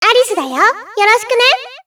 好奇心旺盛で元気な女の子。
サンプルボイス「